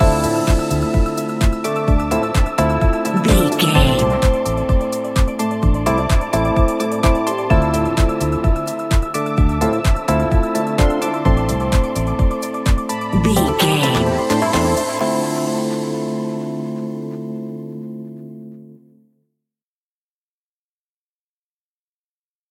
Aeolian/Minor
groovy
uplifting
energetic
drum machine
synthesiser
electric piano
bass guitar
funky house
deep house
nu disco
upbeat
funky guitar
synth bass